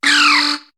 Cri de Tritonde dans Pokémon HOME.